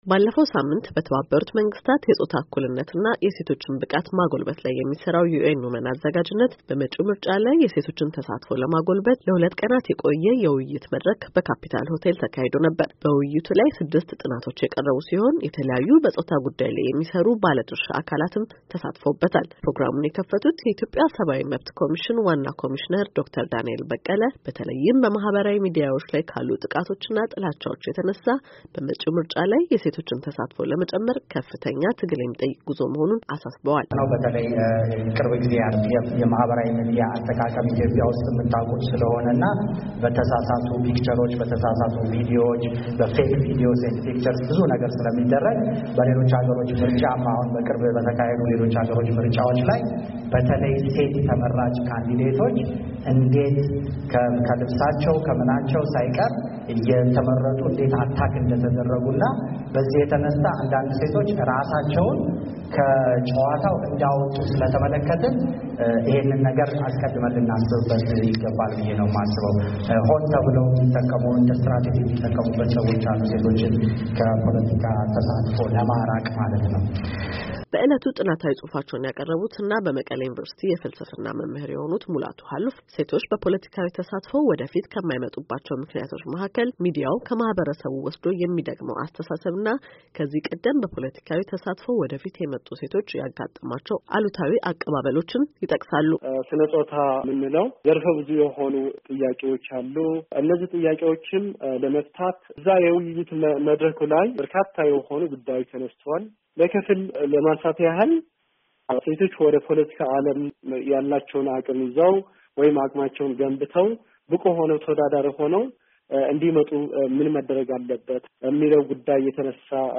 ሶስት ምሁራንን አነጋግራ ተከታዩን ዘገባ አሰናድታለች፡፡